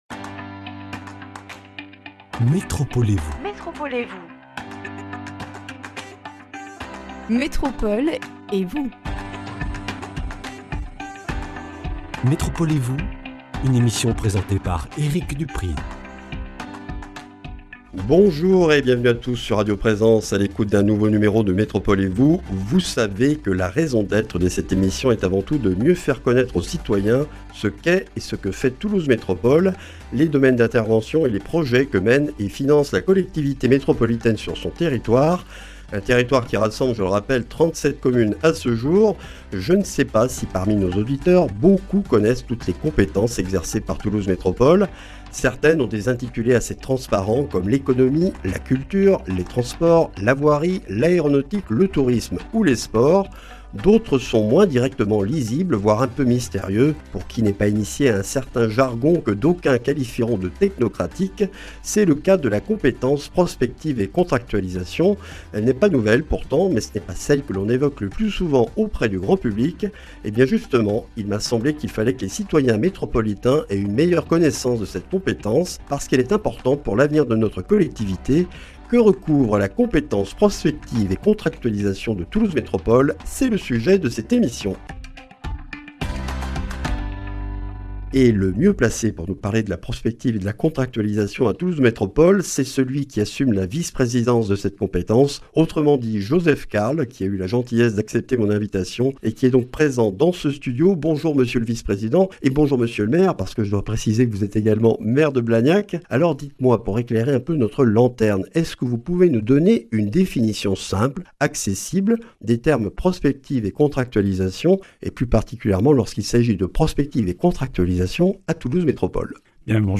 Qu’est-ce que la mission Prospective et Contractualisation de Toulouse Métropole ? Un éclairage et des explications avec Joseph Carles, maire de Blagnac, vice-président de Toulouse Métropole chargé de la Prospective et de la Contractualisation. Une mission mal connue mais cruciale pour affronter la crise en cours et s’adapter aux évolutions qu’elle va entraîner dans notre société et notre économie.